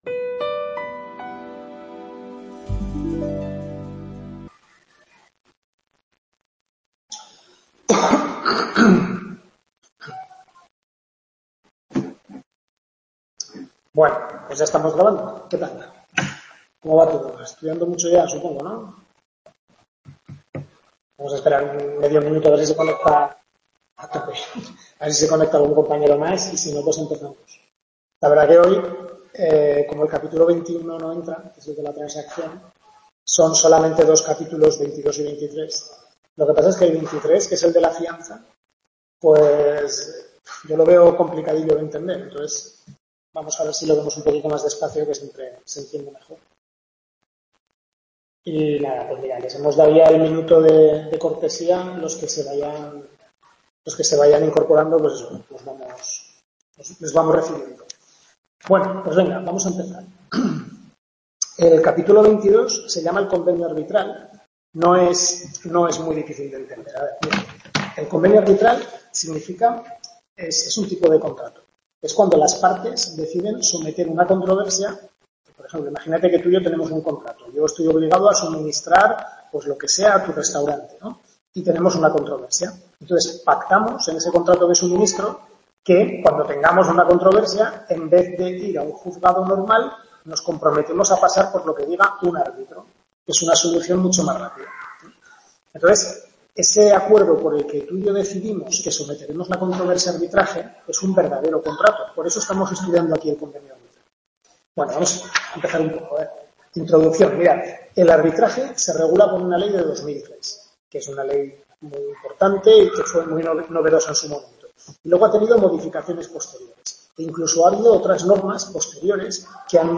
Última tutoría del segundo cuatrimestre de Civil II (Contratos), referida a los capítulos 22 y 23.